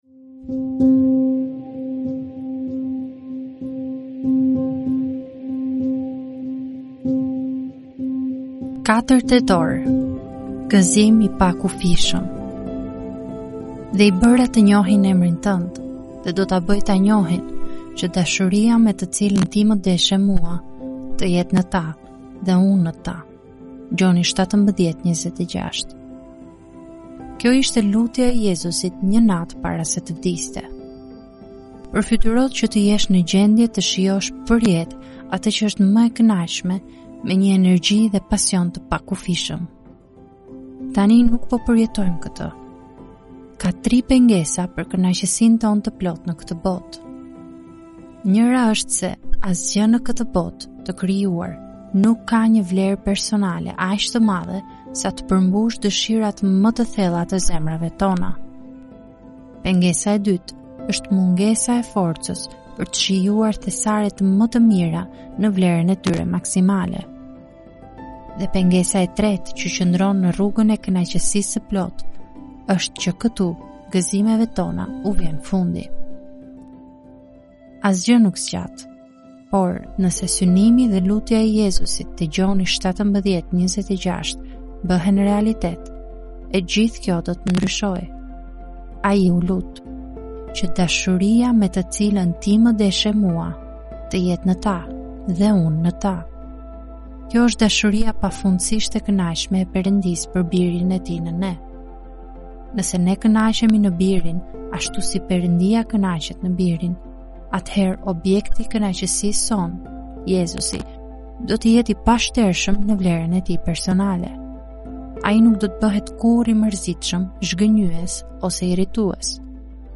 "Solid Joys" janë lexime devocionale të shkruara nga autori John Piper.